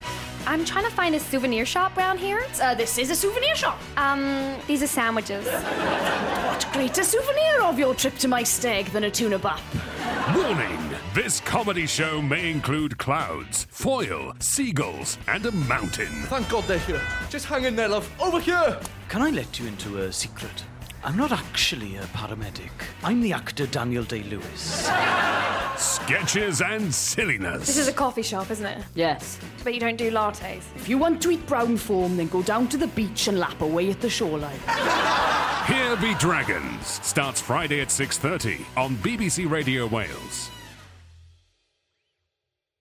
This new sketch series featuring a who's who of up-and-coming Welsh comedy talent. Expect to encounter wrestling, salsa dancing, magpies, laundry, some awful coffee and a wizard.